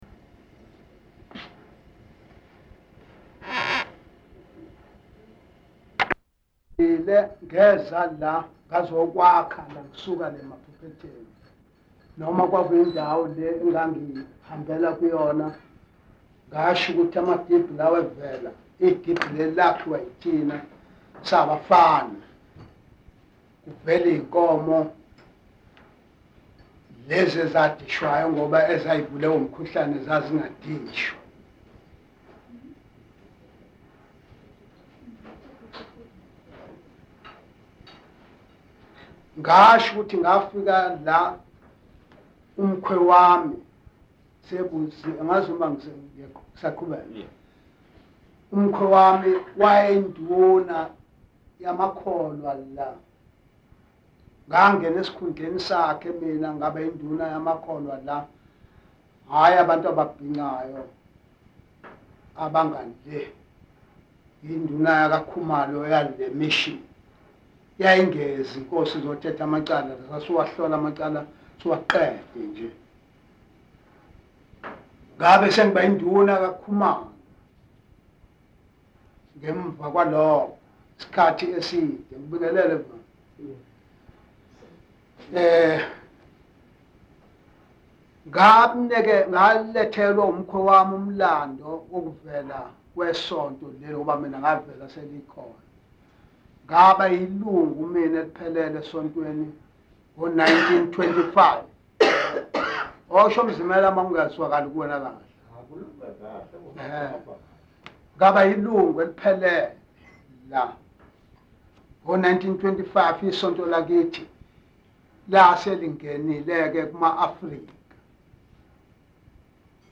Interview-recording